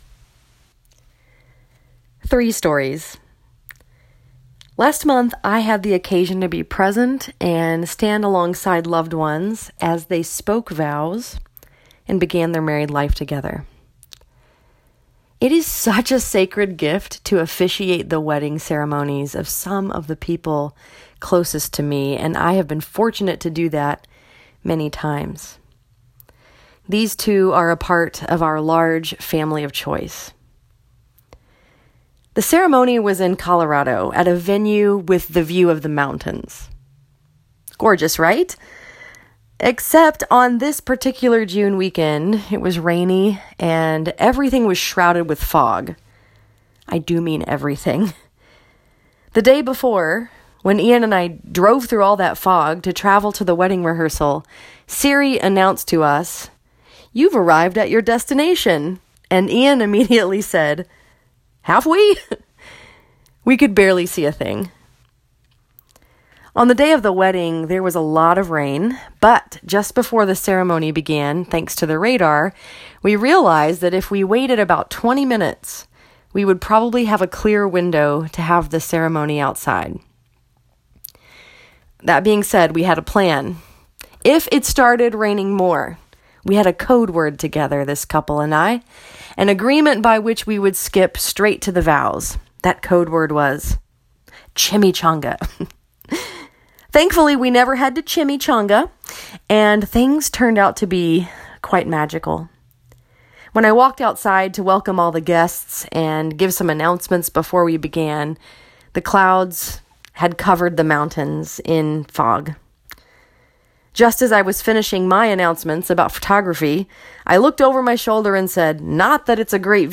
This sermon was preached at Northside Presbyterian Church and was focused upon the story that is told in Luke 10:38-42.